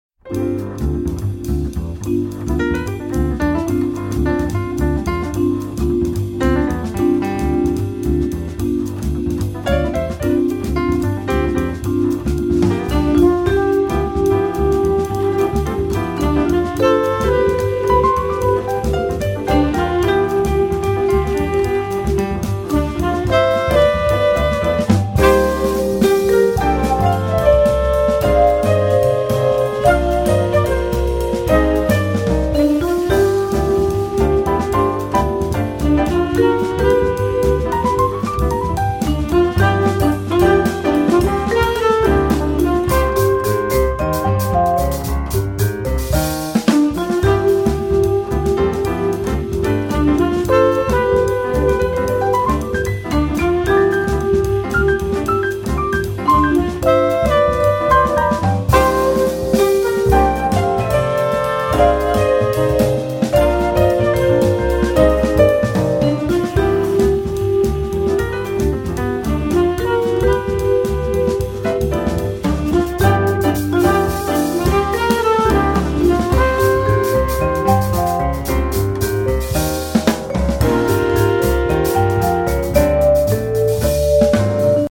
vibrafono
tromba, flicorno
sassofono tenore
flauto
piano
basso
batteria
percussioni
Le melodie leggere e ariose